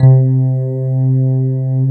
Index of /90_sSampleCDs/USB Soundscan vol.09 - Keyboards Old School [AKAI] 1CD/Partition A/13-FM ELP 1